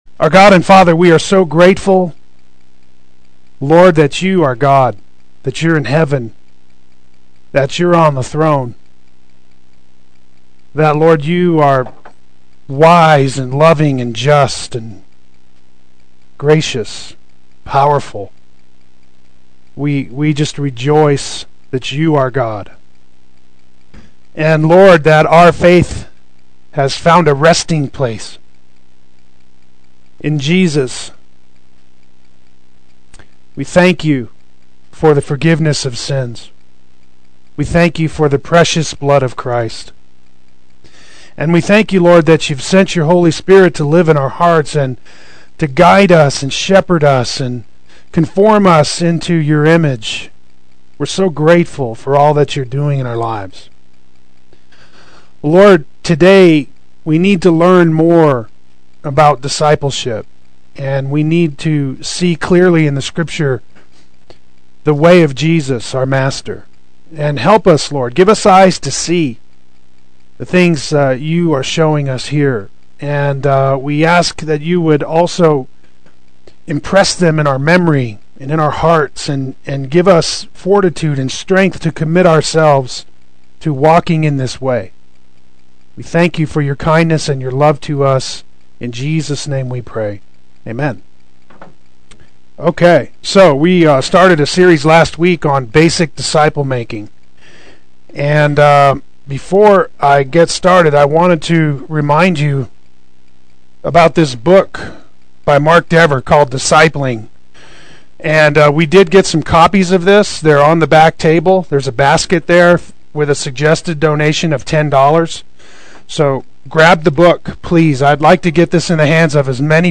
Play Sermon Get HCF Teaching Automatically.
Part 2 Adult Sunday School